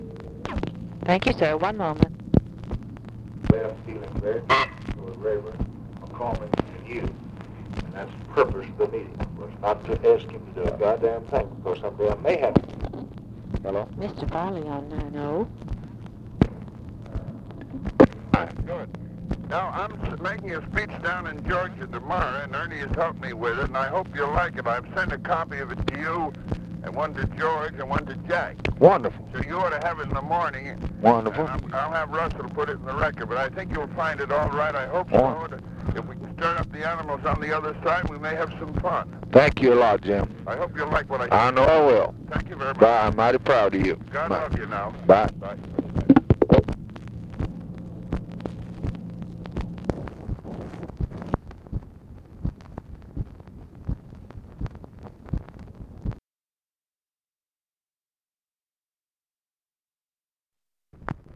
Conversation with JAMES FARLEY and OFFICE CONVERSATION, May 1, 1964
Secret White House Tapes